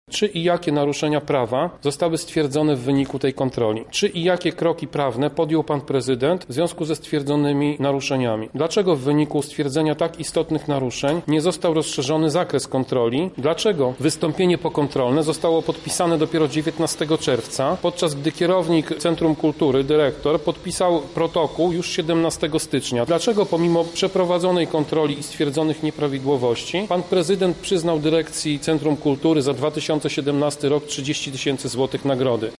-mówi Tomasz Pitucha, przewodniczący klubu radnych PiS w Radzie Miasta Lublin.